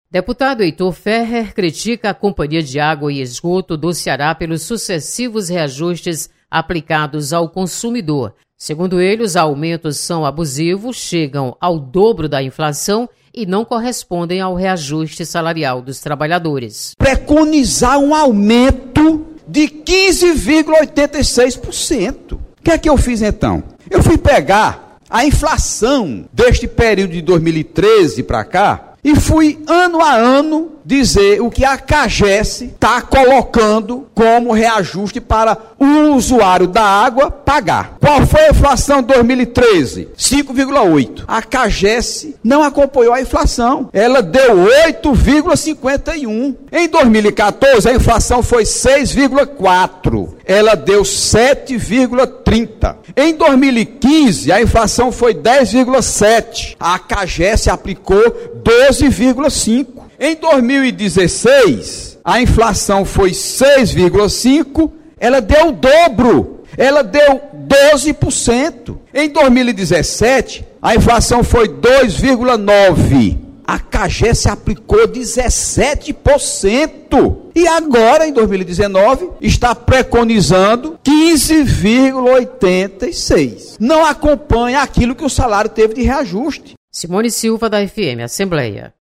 Deputado Heitor Férrer considera abusivo reajustes de tarifas da Cagece. Repórter